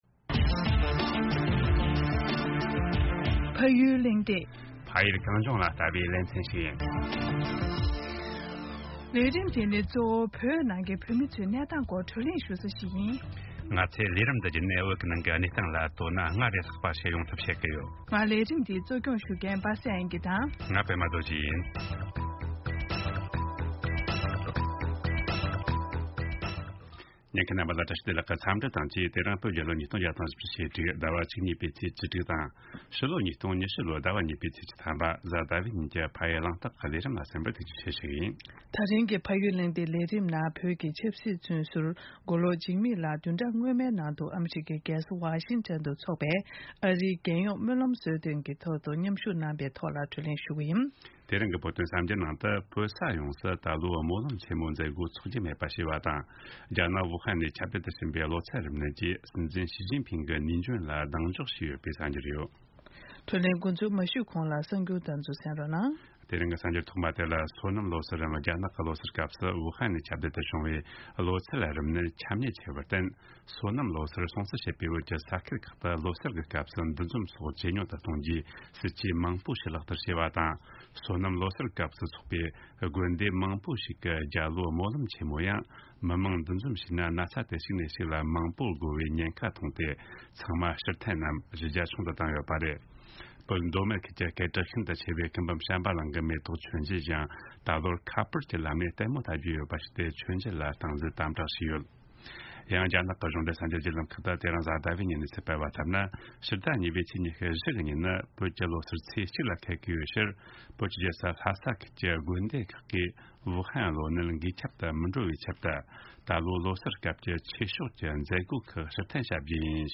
བགྲོ་གླེང་ཞུས་པ་ཞིག་གསན་རོགས་གནང་།